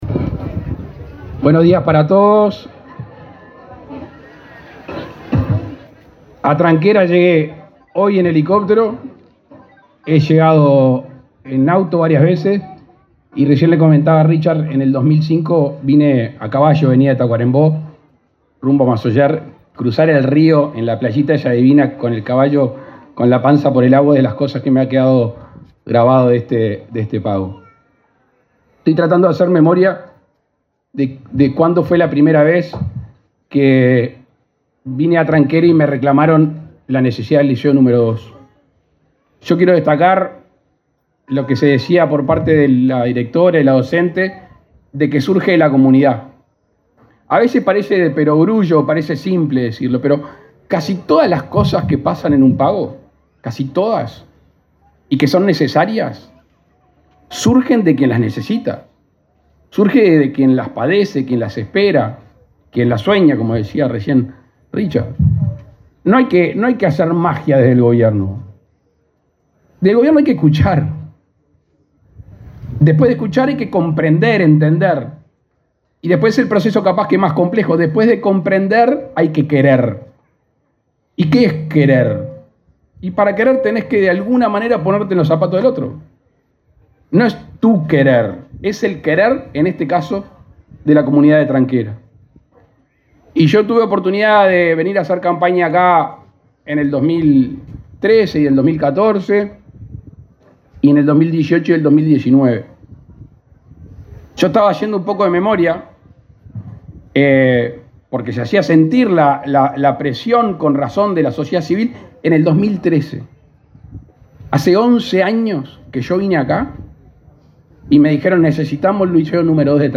Palabras del presidente Luis Lacalle Pou
El presidente de la República, Luis Lacalle Pou, encabezó, este jueves 7 en la localidad de Tranqueras, Rivera, la inauguración de un liceo.